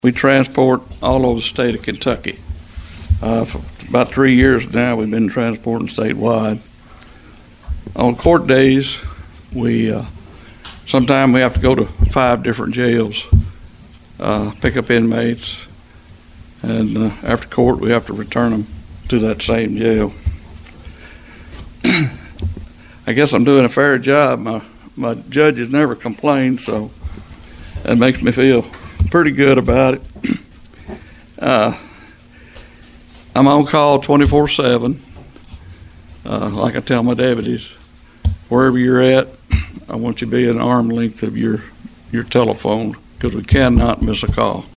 Trigg County Republican Jailer candidates touted experience, community service, and training during the recent republican party meet the candidates event in Cadiz.
Incumbent Jailer James Hughes discussed his 18 years of experience in office, always coming under budget, and providing service when needed to transport prisoners.